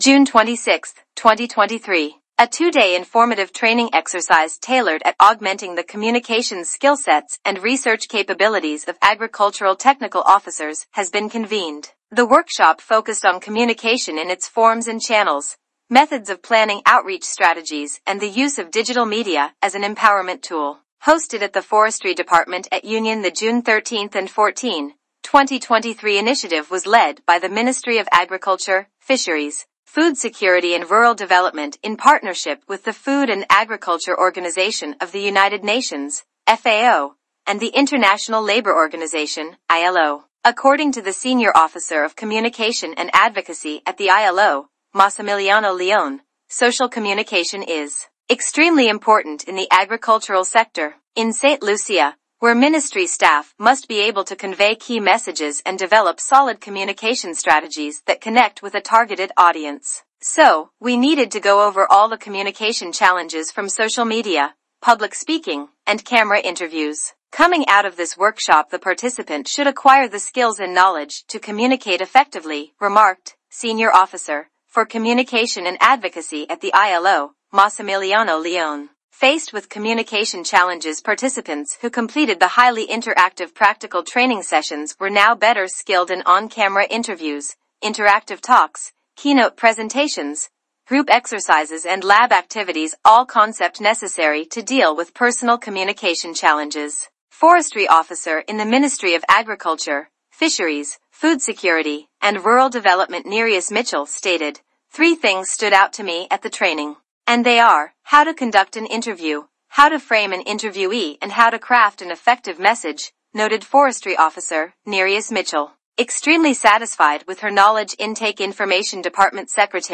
Play Press Release